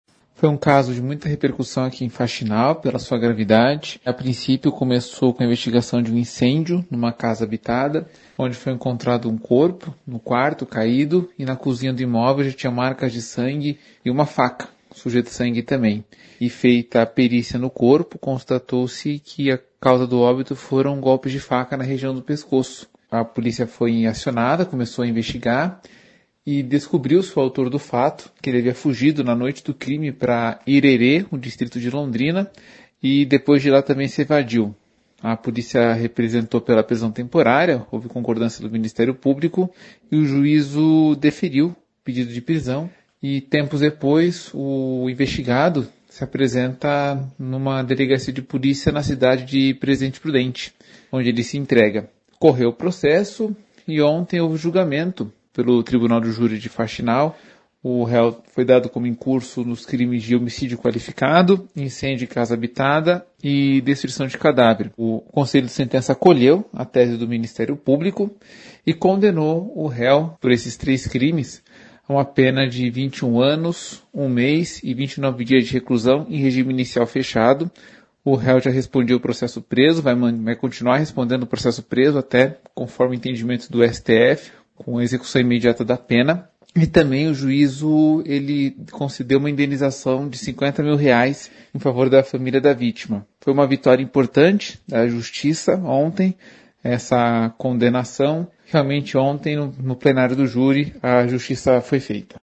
Ouça o que diz o promotor de Justiça, Gabriel Thomaz da Silva.